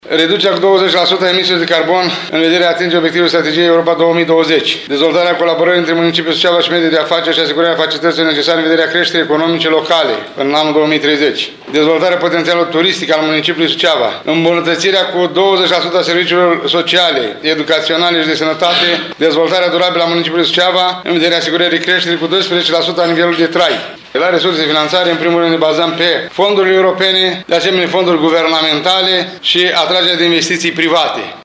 Primarul ION LUNGU detaliază o parte din capitole.